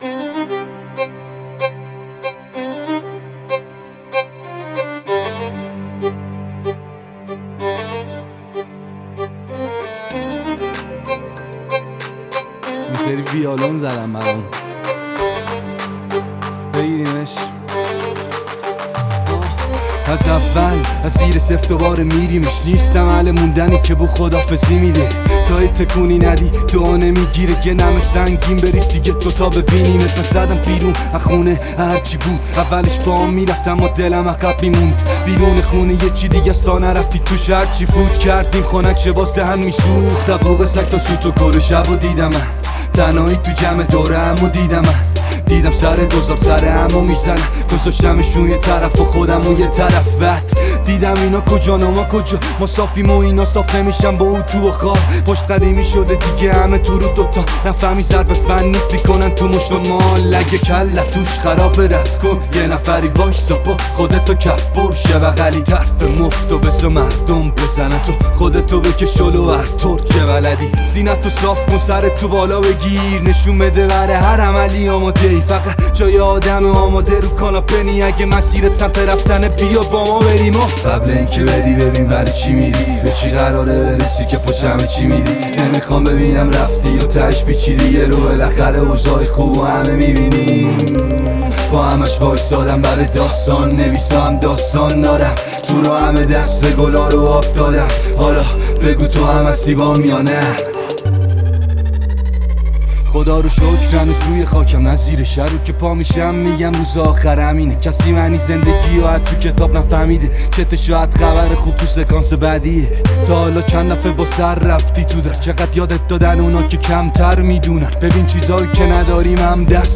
اهنگ انگیزشی برای درس
رپ انگیزشی